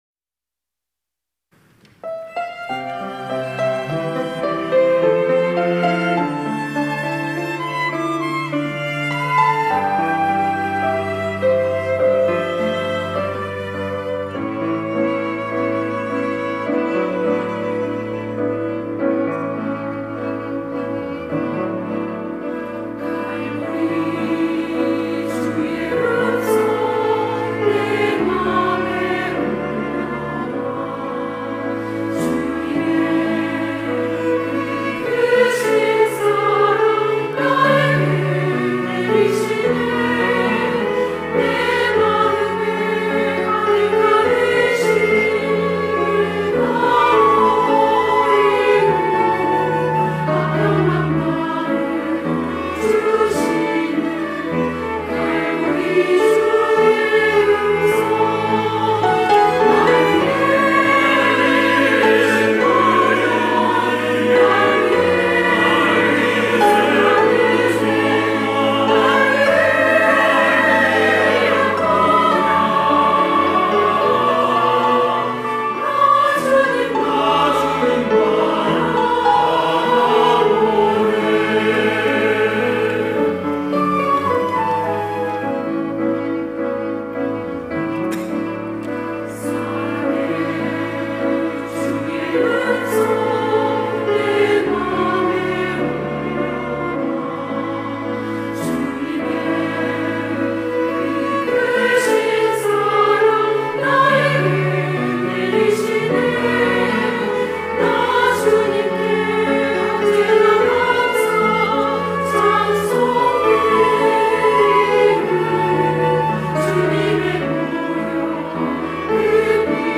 할렐루야(주일2부) - 보혈의 은혜
찬양대